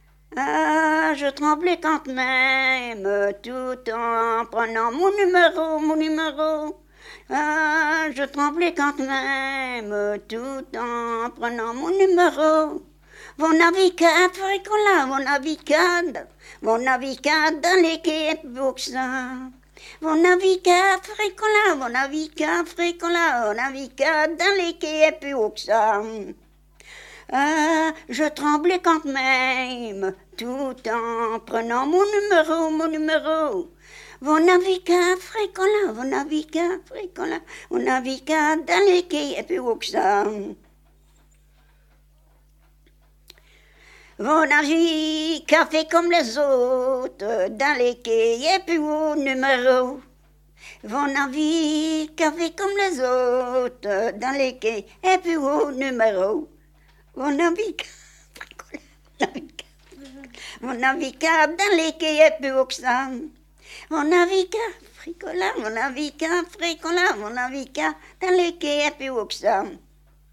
Genre : chant
Type : chanson de conscrit / tirage au sort
Lieu d'enregistrement : Vierves-sur-Viroin
Support : bande magnétique